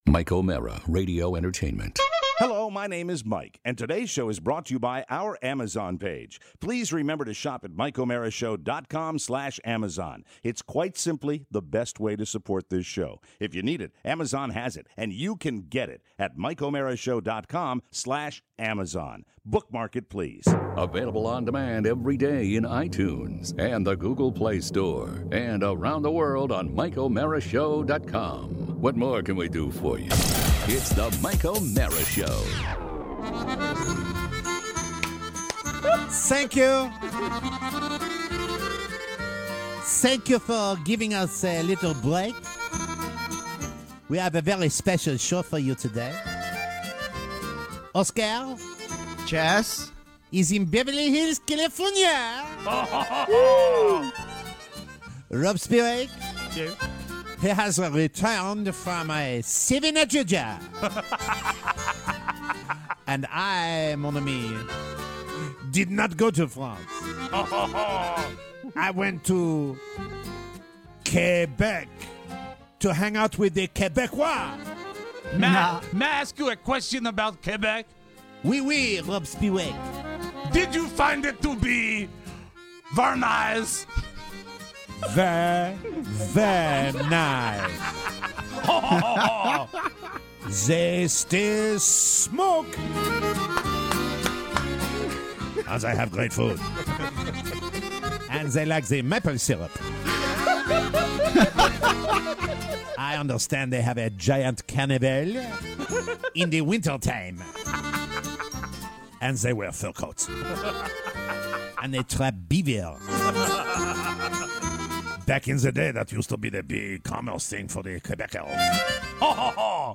A wonderful return with a tricorner broadcast! Plus, bad vacations, good vacations, the French, Uber fun & celebrity sightings.